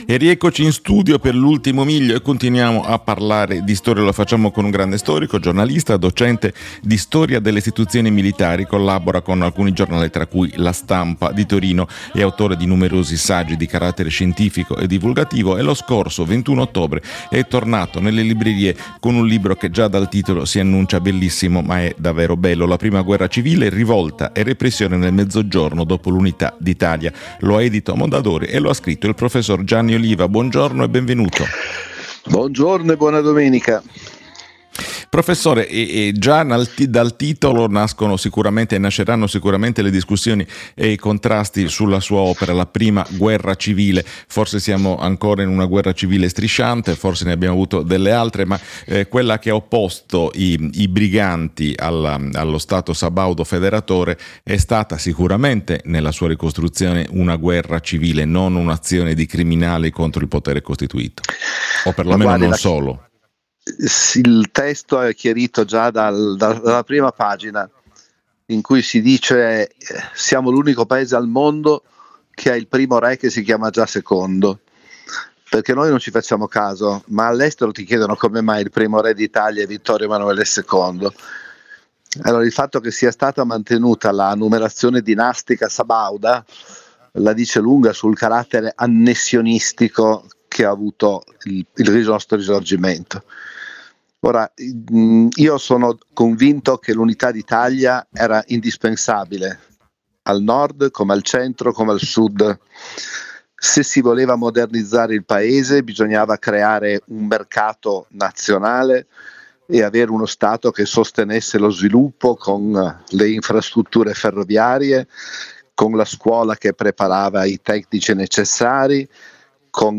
Qui di seguito l’intervista andata in onda su Giornale Radio, la radio libera di informare domenica 23 novembre 2025 nel programma radiofonico “IL PUNTO G”